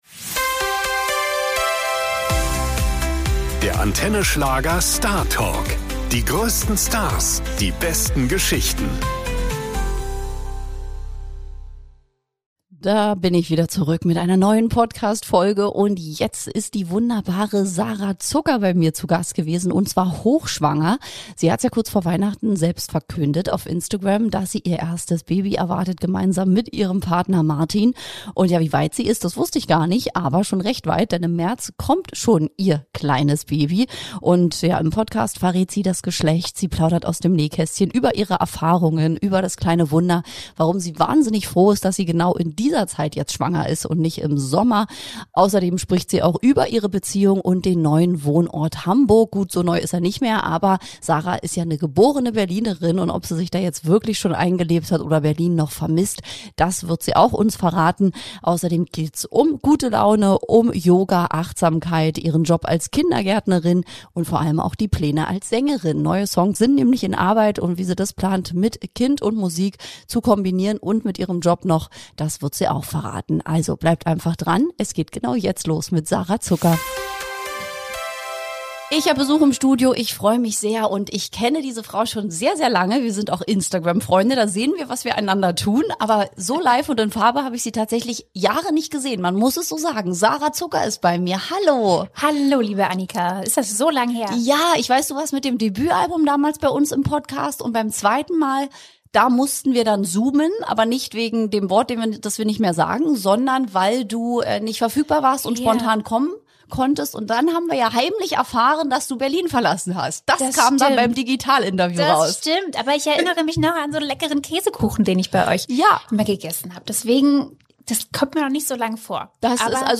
Hochschwanger war sie im Podcast-Studio zu Gast